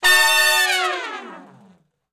014 Long Falloff (B) har.wav